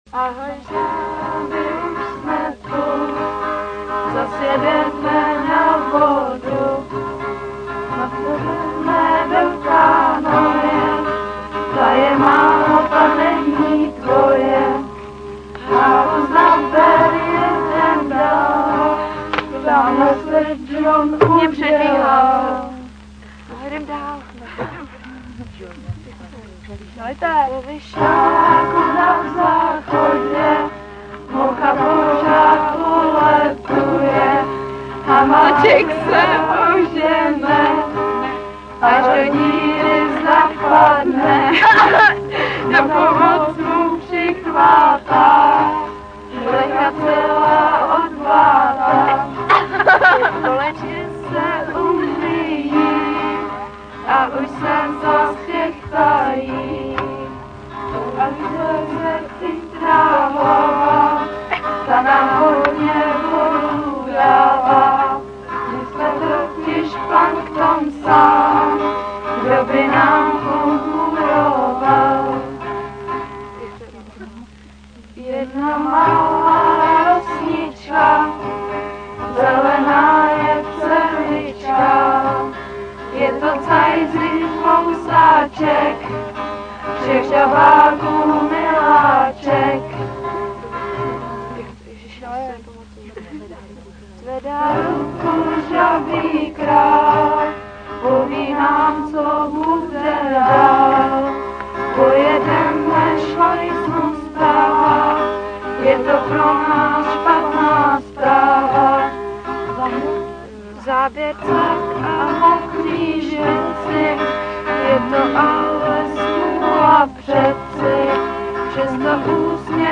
Jak už sám název praví, je to nezávislá autorsko-interpretační žabí soutěž, jejíž finále vypukne s železnou pravidelností vždy ke konci tábora.